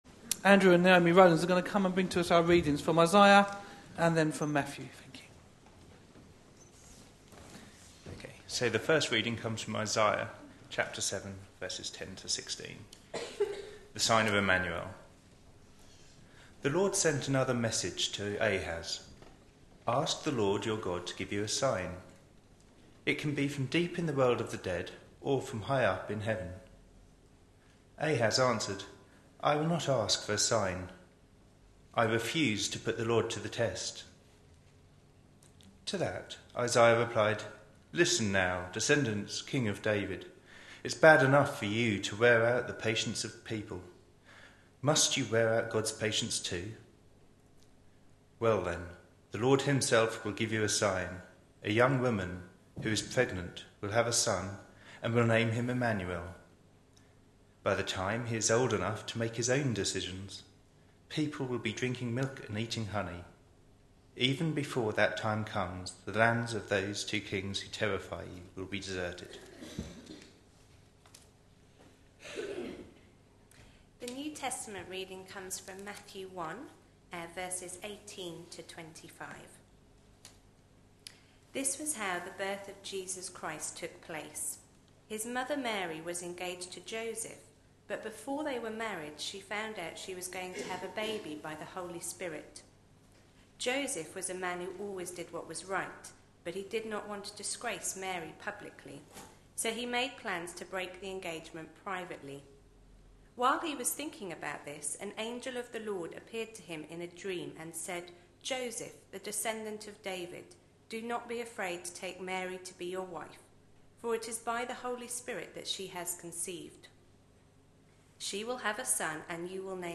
A sermon preached on 14th December, 2014, as part of our Advent 2014. series.